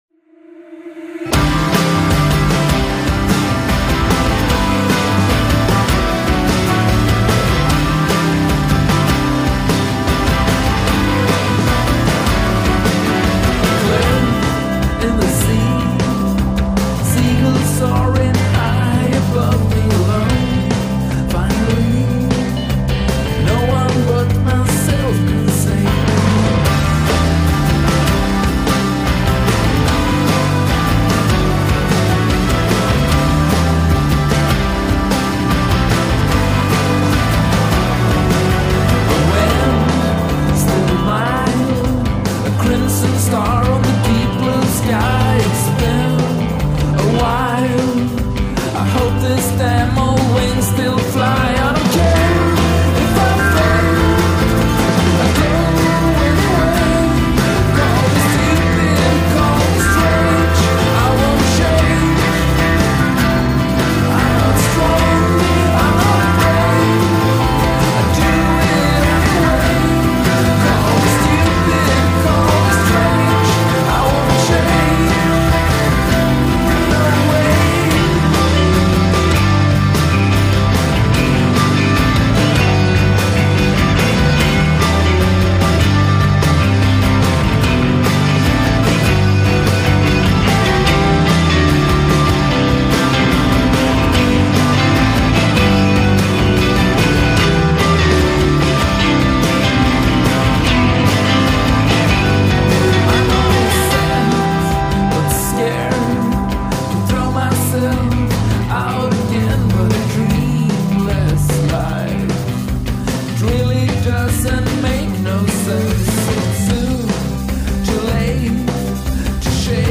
indie rock quartet
Drummer
singer and guitarist
bass player